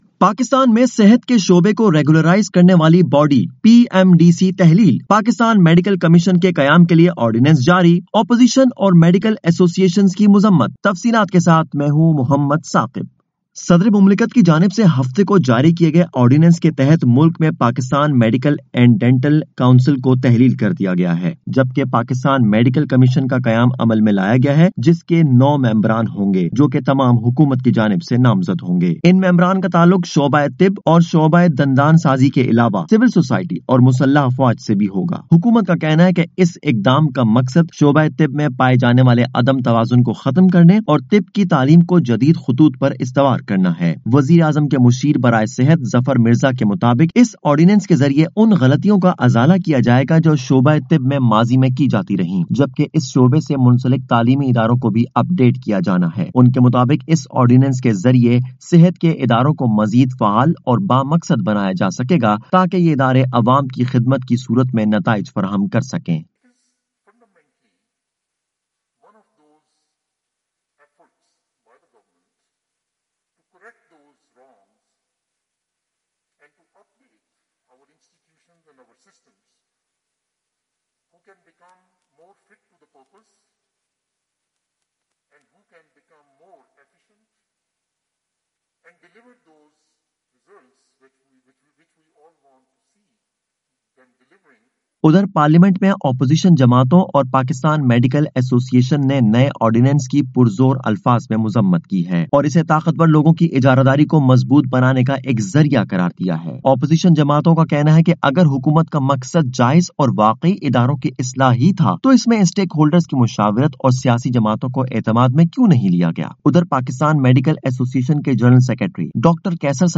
رپورٹ